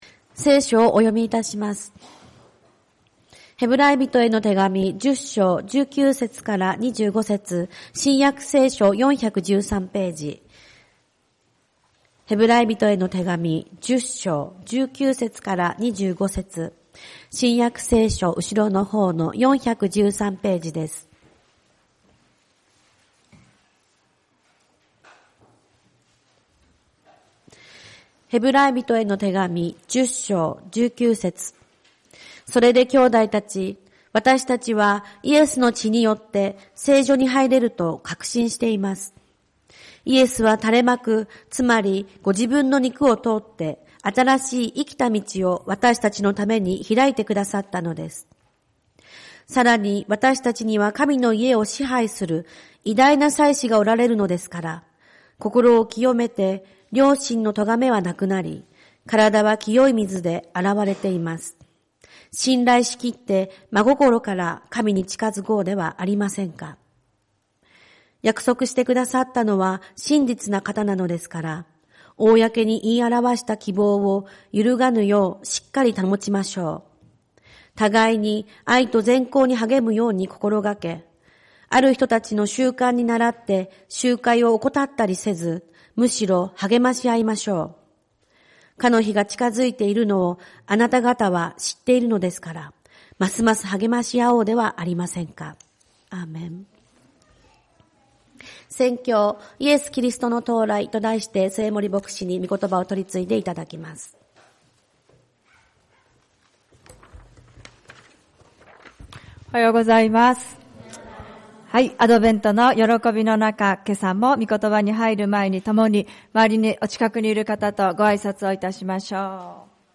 主日礼拝 「イエス・キリストの到来」 ヘブライ人への手紙10:19-25